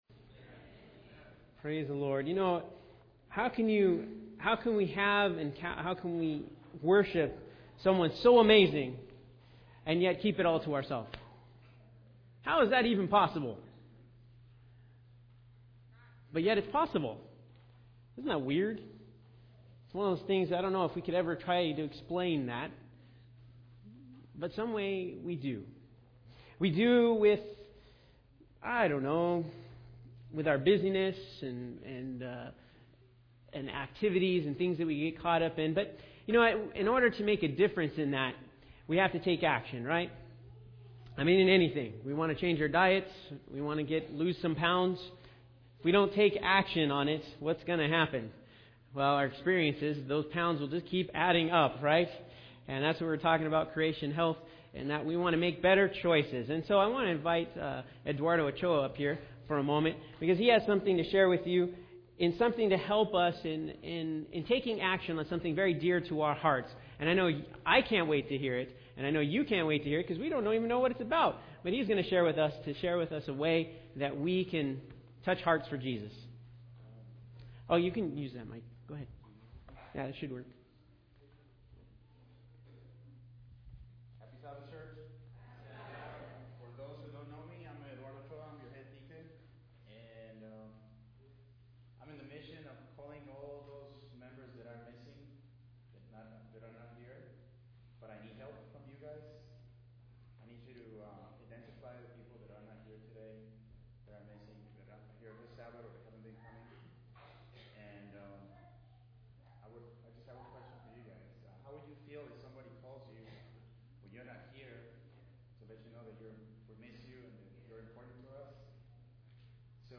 2015 Sermons